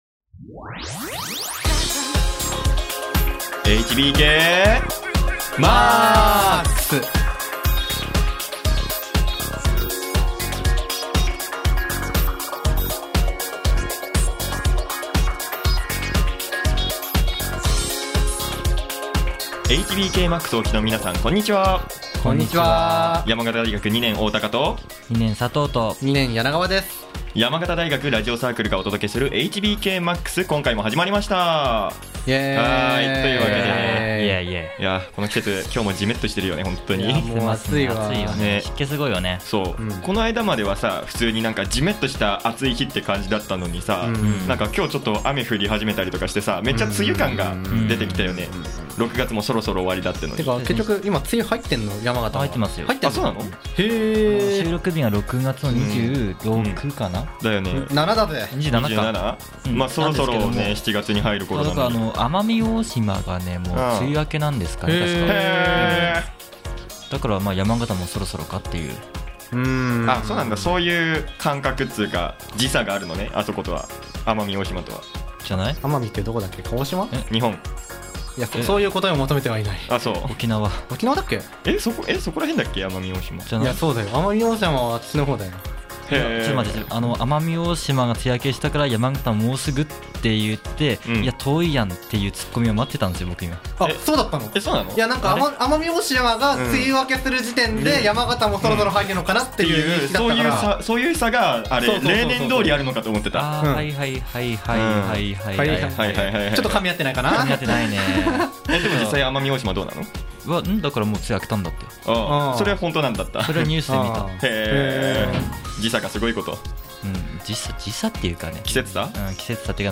パーソナリティーの愉快なトーク、そして様々な企画をお届けしていきます。